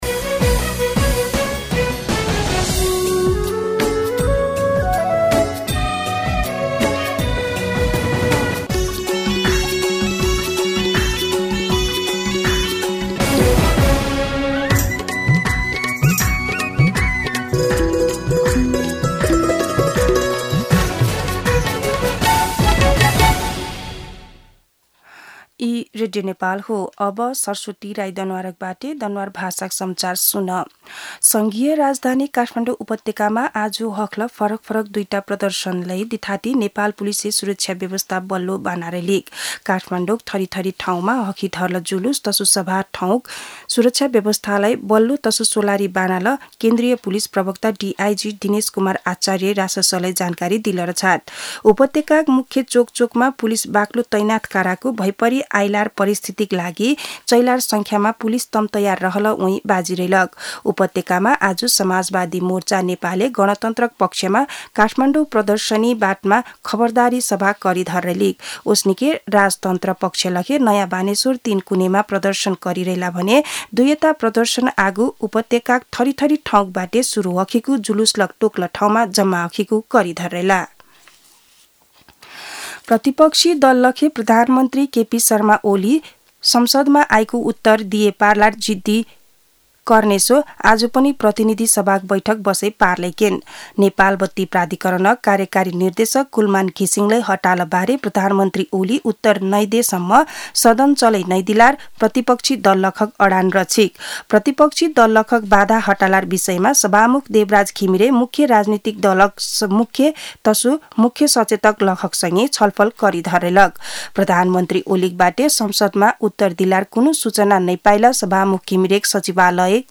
दनुवार भाषामा समाचार : १५ चैत , २०८१
Danuwar-News-3.mp3